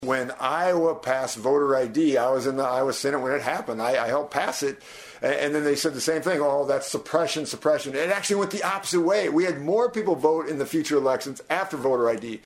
FEENSTRA MADE HIS COMMENTS DURING AN INTERVIEW AT KSCJ.